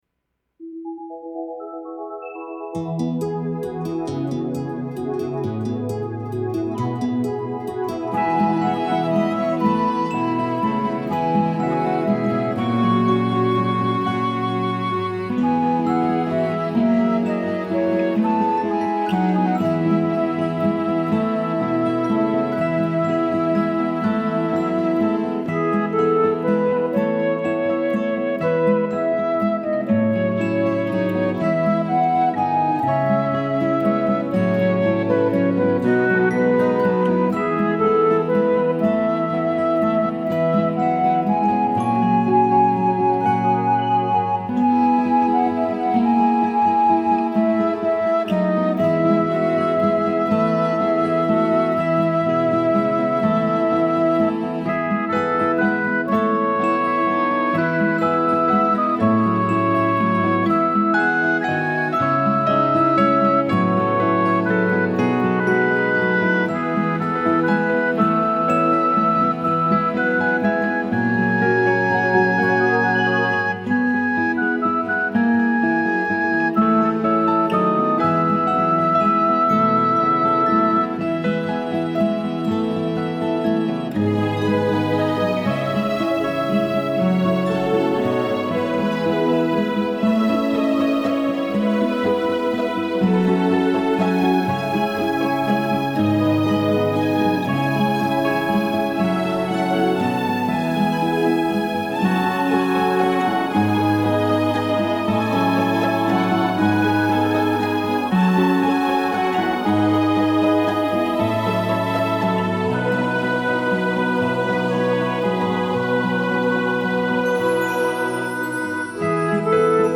Clear Instrumental 2011
clear-2-instrumental-1.mp3